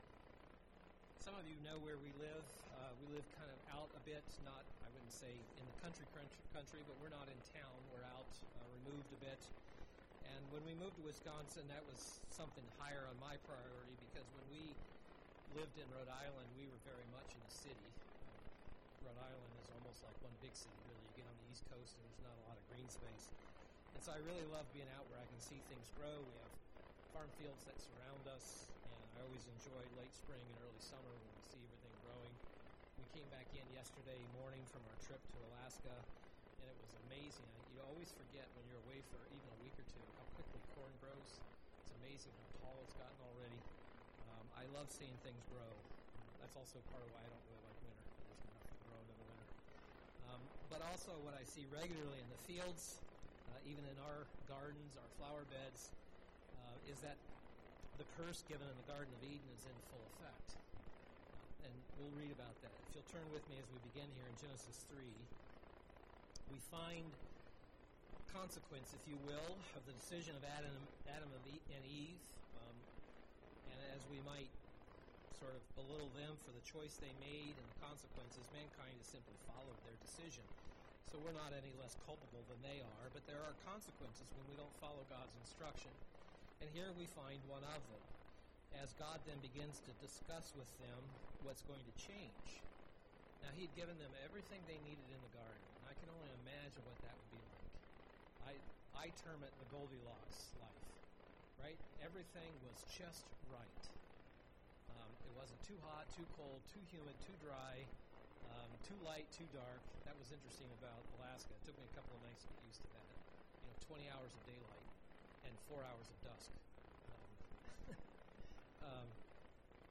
Given in Milwaukee, WI
UCG Sermon parable wheat wheat and the tares Matthew 13 Studying the bible?